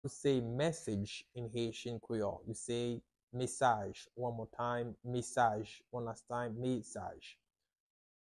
How to say "Message" in Haitian Creole - "Mesaj" pronunciation by a native Haitian tutor
“Mesaj” Pronunciation in Haitian Creole by a native Haitian can be heard in the audio here or in the video below:
How-to-say-Message-in-Haitian-Creole-Mesaj-pronunciation-by-a-native-Haitian-tutor.mp3